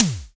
Index of /90_sSampleCDs/Roland L-CD701/SNR_Analog Snrs/SNR_Analog Snrs